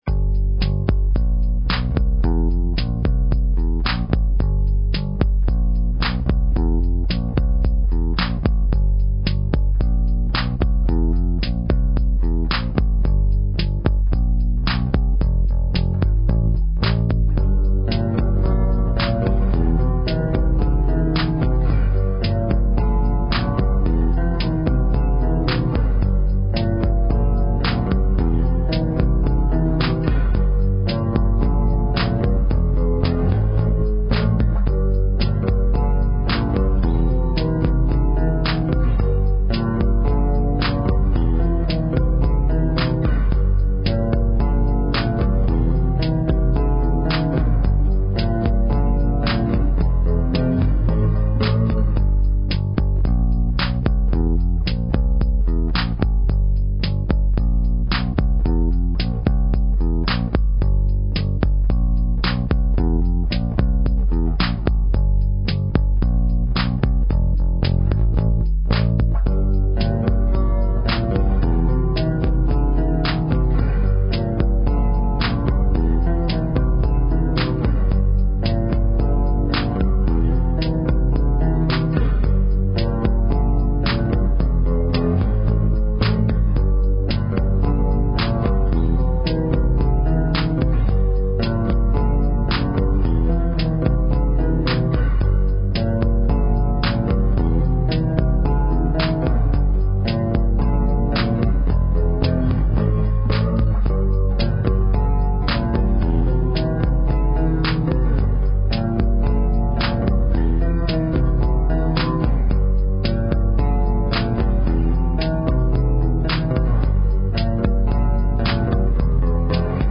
Bass
F/Dm 98bpm